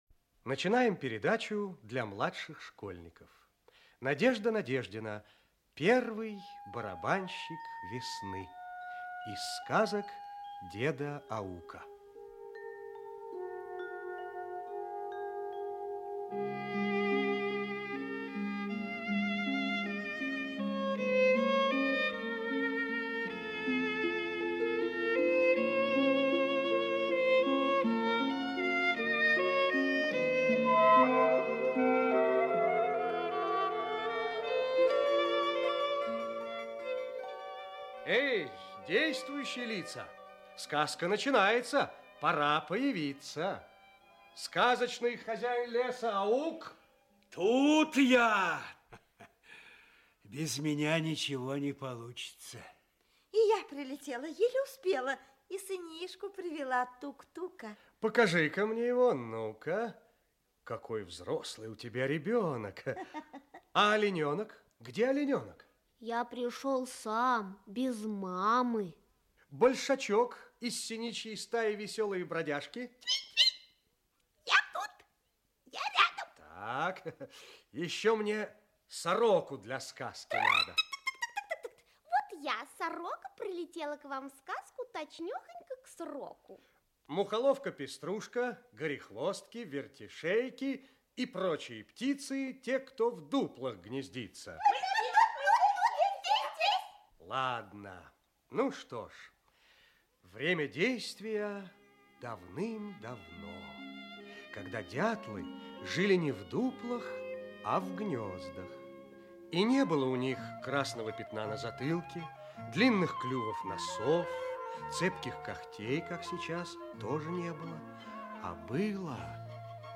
Аудиокнига Первый барабанщик весны | Библиотека аудиокниг